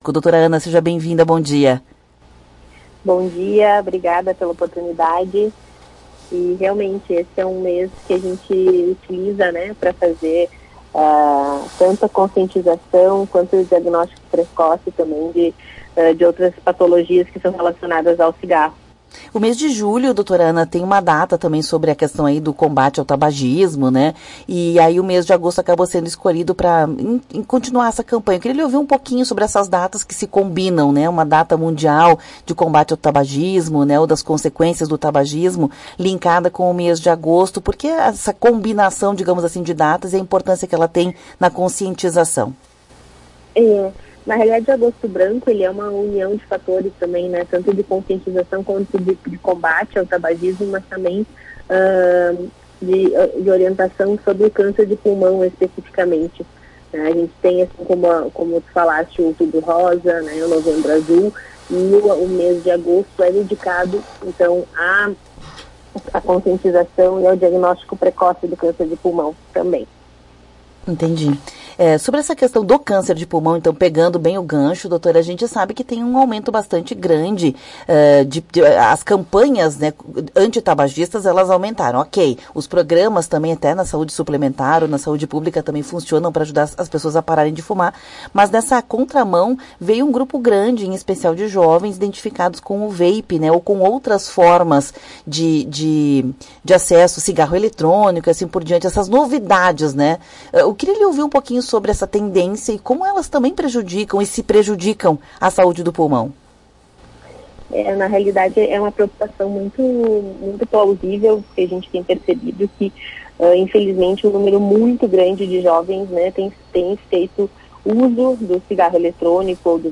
Médica oncologista faz o alerta